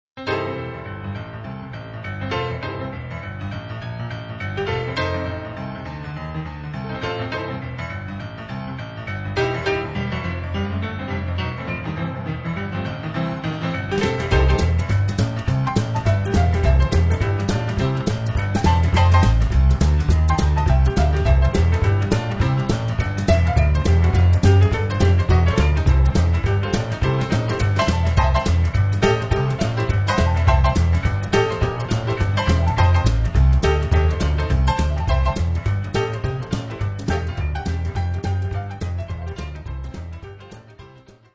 PIANO
Boogie-Woogie-Trio  :